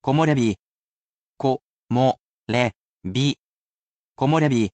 I personally sound out each word or phrase aloud for you to repeat as many times as you wish, and you can ask me to say it as many times as you wish.